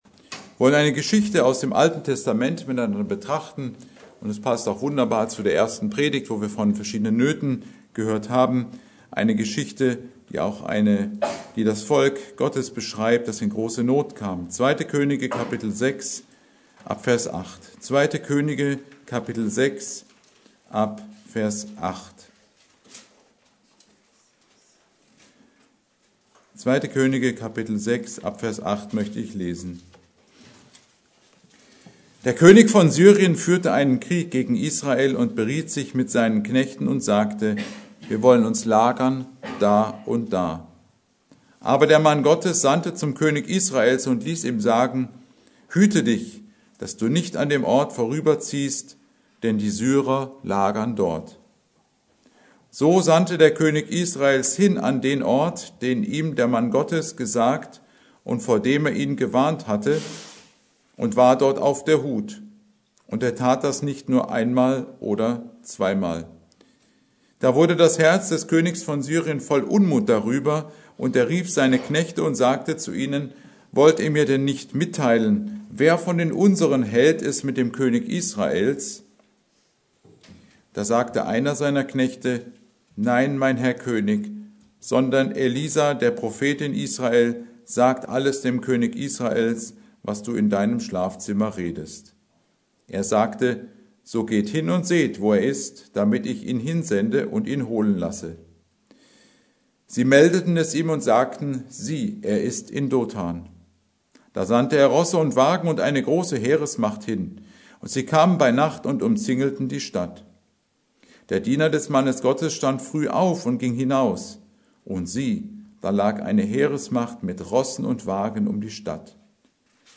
Predigt: Der rechte Glaubensblick
Serie: Gottesdienste Wegbereiter-Missionsgemeinde Passage: 2. Könige 6,8